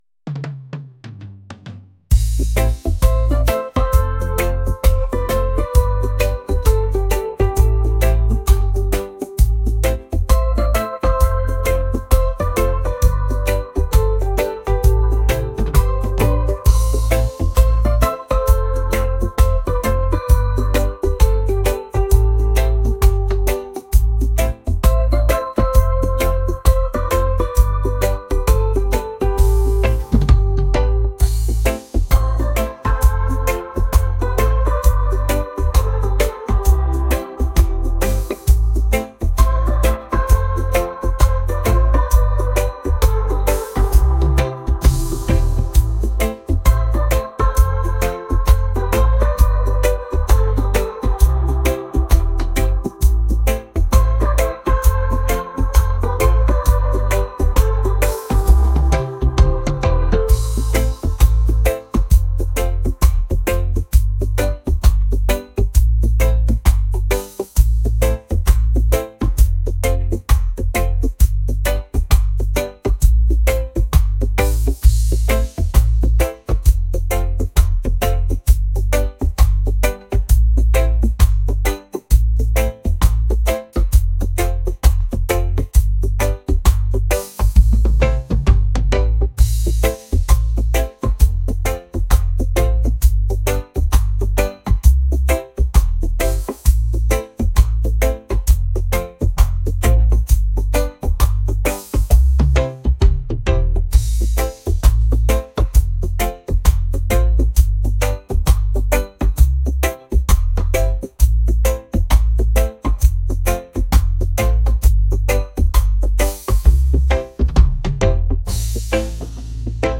reggae | acoustic | soul & rnb